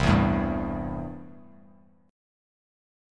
Listen to C6 strummed